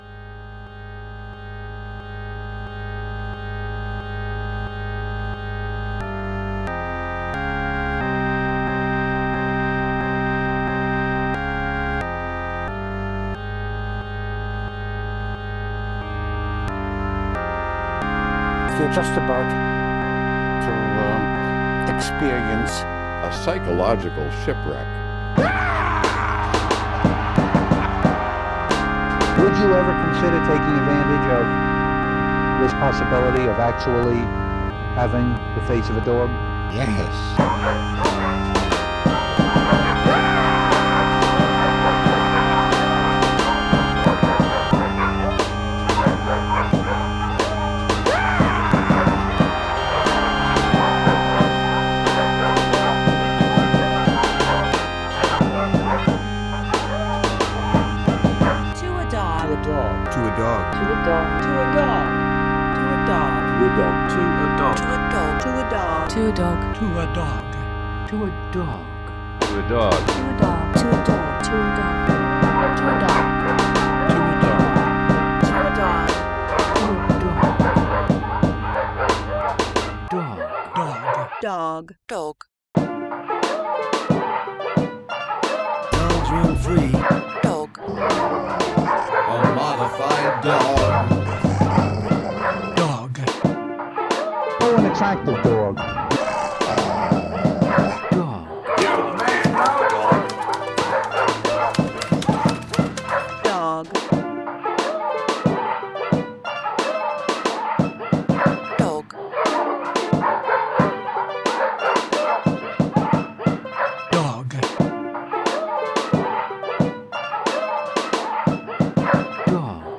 collage music